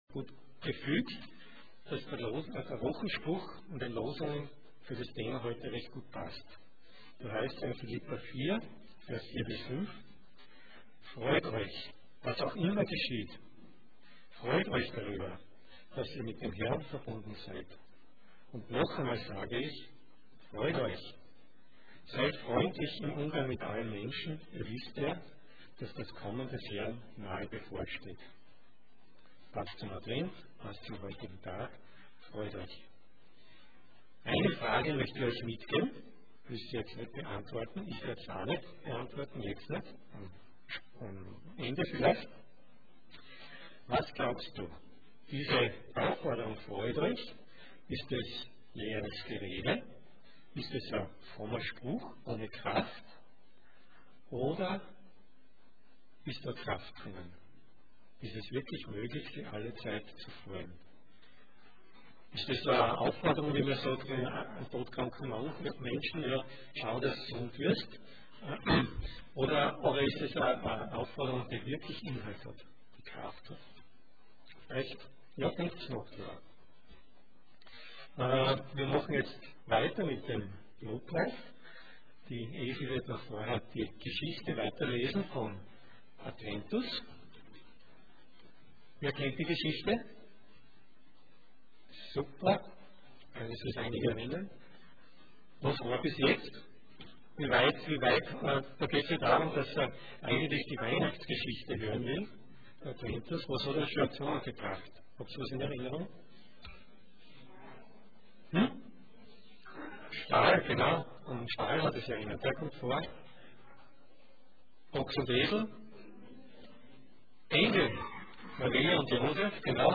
Sonntag Morgen